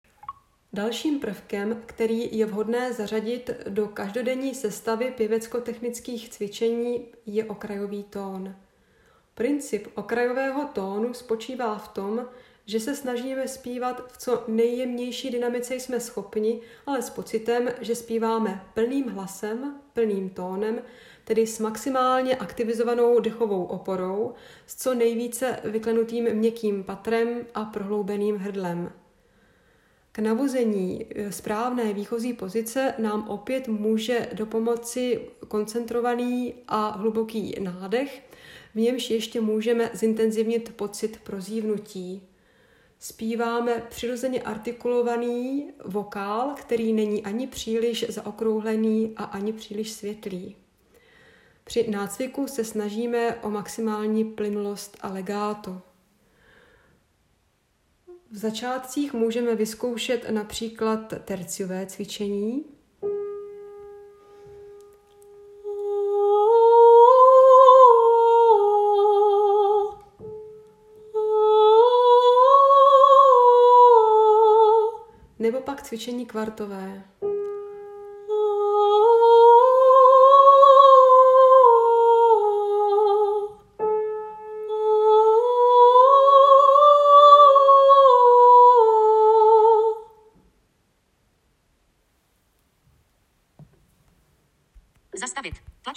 FF:HV_15f Hlasová výchova
Okrajovy_ton.m4a